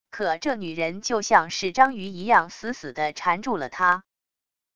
可这女人就像是章鱼一样死死地缠住了他wav音频生成系统WAV Audio Player